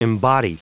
Prononciation du mot embody en anglais (fichier audio)
Prononciation du mot : embody